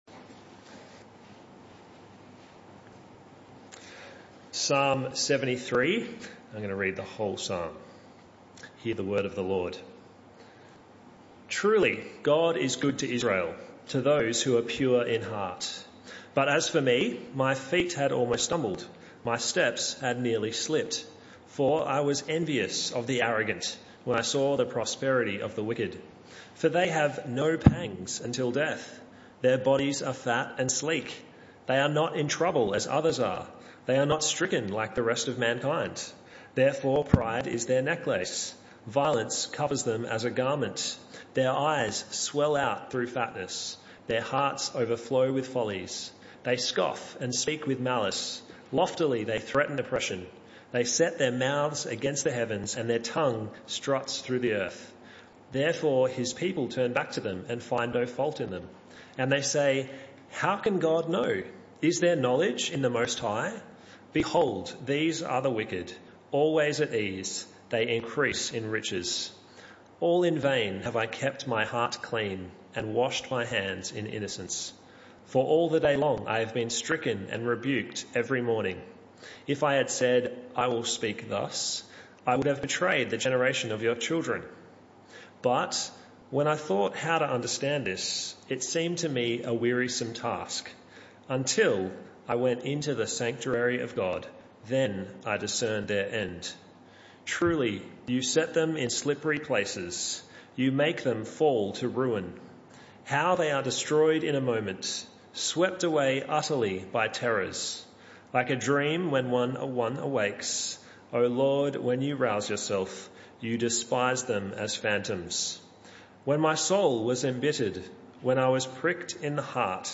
One-Off Talks Passage: Psalm 73 Service Type: Evening Service Speaker